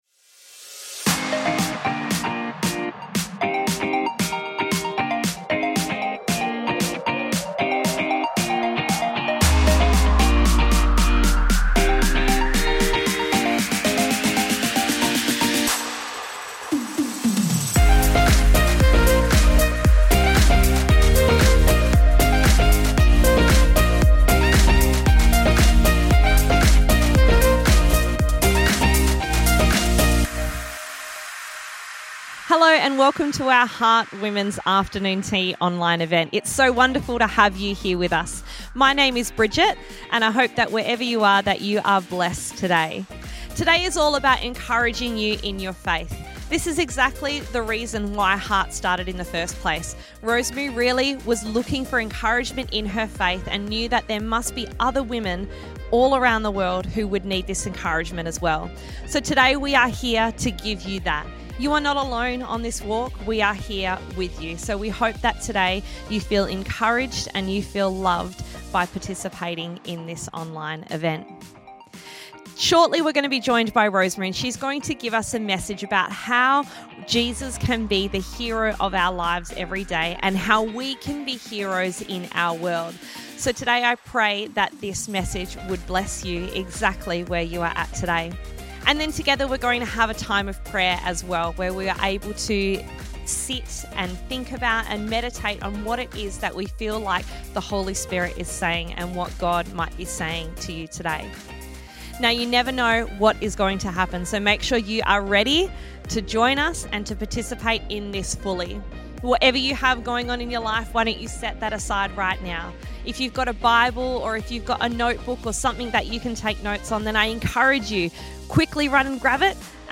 This message was recorded at an afternoon tea and released for our online event – A Time with Heart. It encourages us to love our neighbours as our friends.